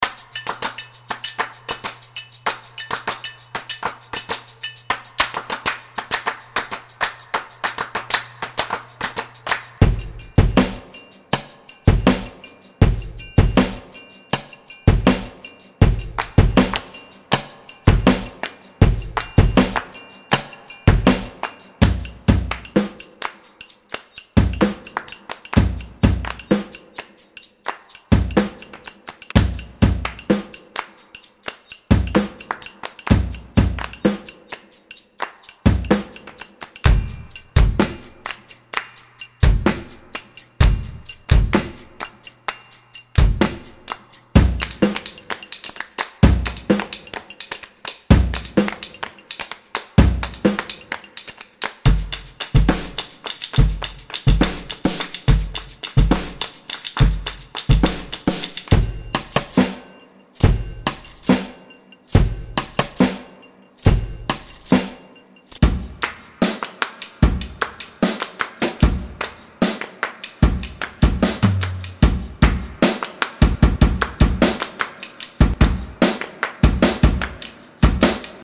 These loops are perfect for Folk, Pop and Chillout tracks.